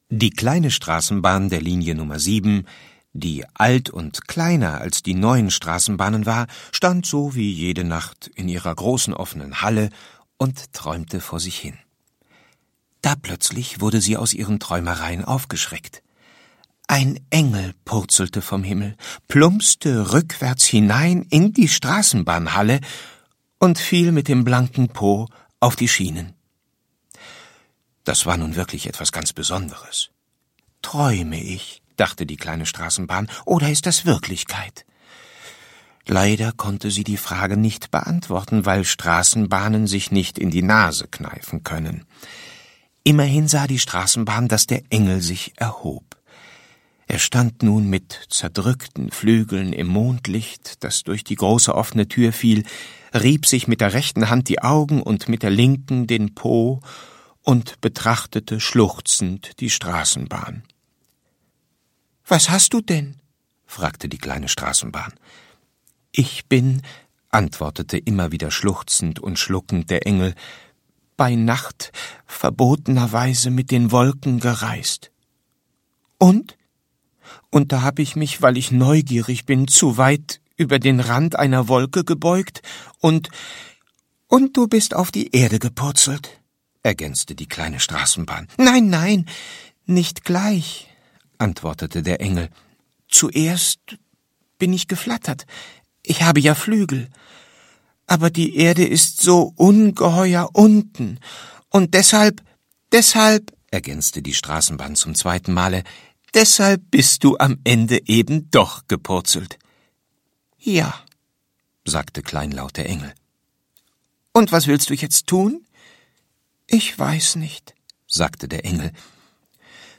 Auf zwei CDs versammelt diese Hörbuchbox die schönsten Geschichten zum Einschlafen, Kuscheln und Träumen. Auf der dritten CD erklingen bekannte und beliebte Schlaflieder.
Schlagworte Einschlafen • Gute-Nacht-Geschichten • Gute-Nacht-Lieder • Hörbuch; Literaturlesung • Kinder/Jugendliche: Anthologien • Schlaflieder